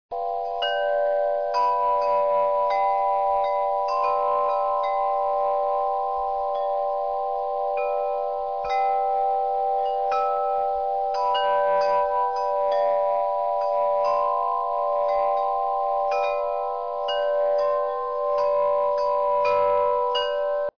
WIND CHIME
Total Lenght  ~ 42"  with 6 Aluminum Pipes of  ~ 1_1/4" Diameter.
...  B  D  F  G  B  C  ;   B min b 5 / b 6 / b 9
SOUND  is named  » Himalayan Echo «
windchime.mp3